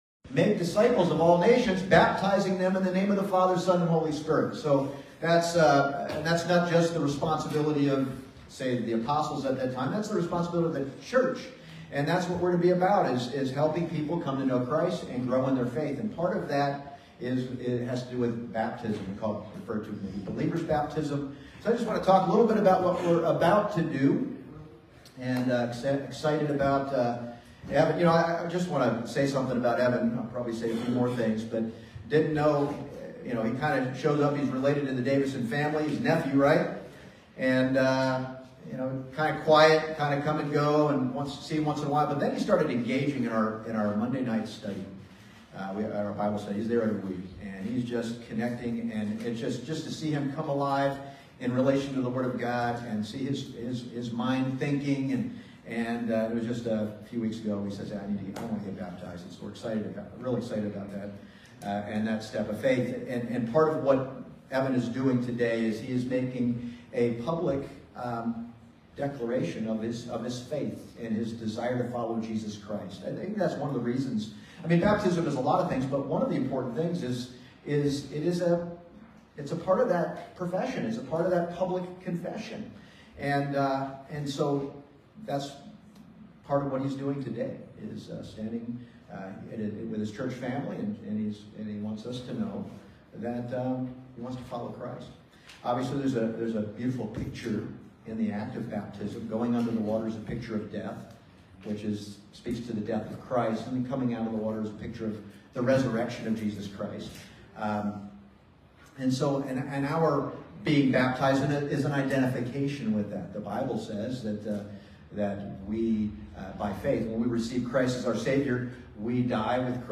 Passage: Romans 5:15-20 Service Type: Sunday Morning